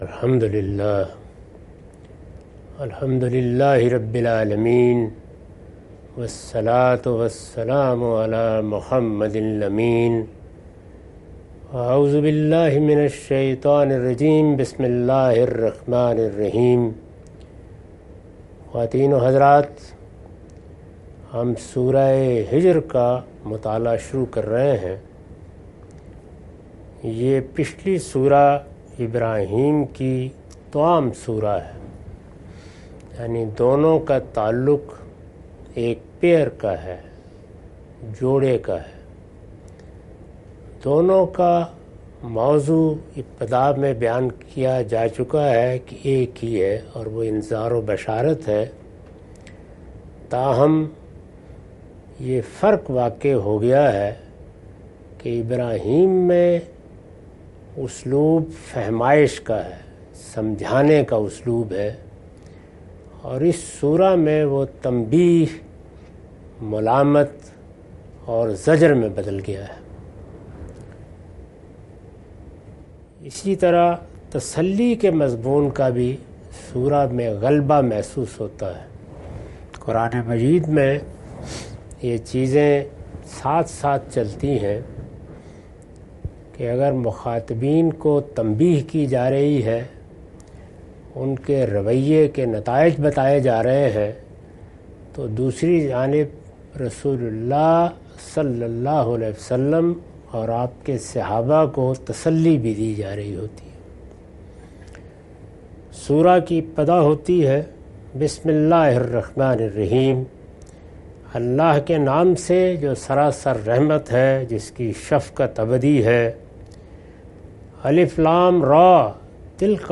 Surah Al-Hijr- A lecture of Tafseer-ul-Quran – Al-Bayan by Javed Ahmad Ghamidi. Commentary and explanation of verses 01-09.